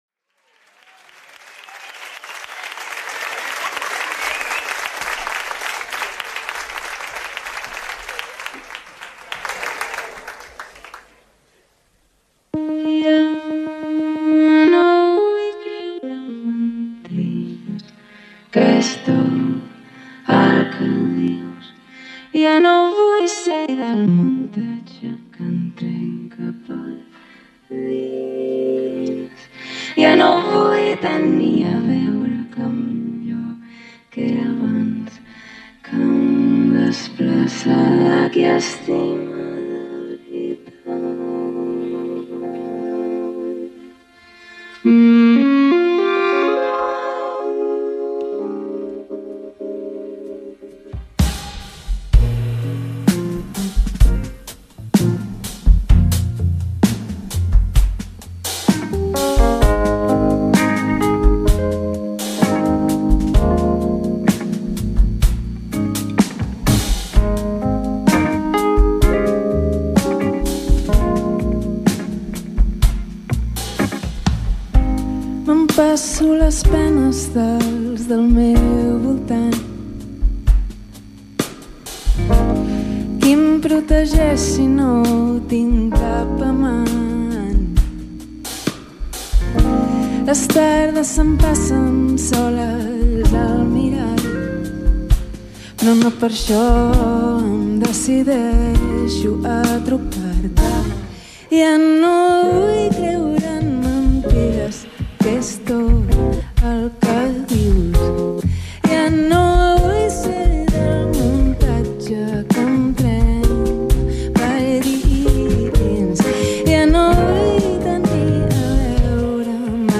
piano
perfecting the styles of jazz, soul, gospel and Latin